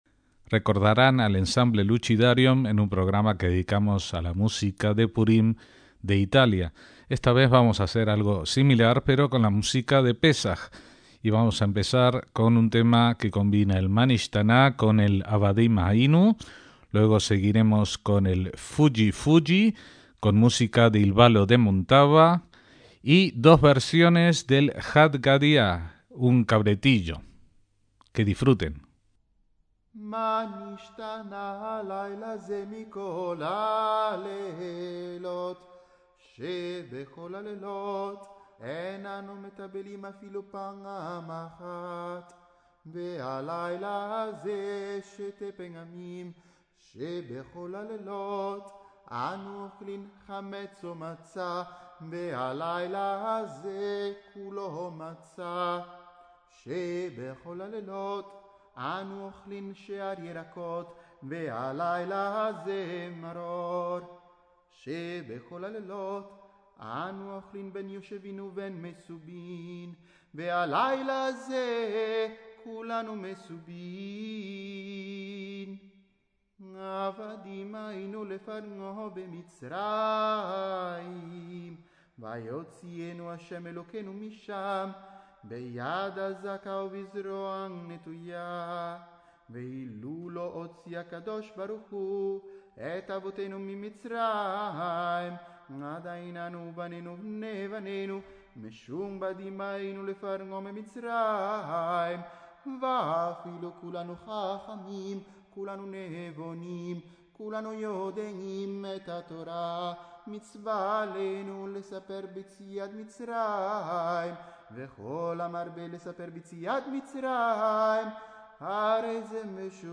MÚSICA DE PÉSAJ
los instrumentos renacentistas de viento
las cuerdas pulsadas
las percusiones y el dulcimer de macillos
la viola da gamba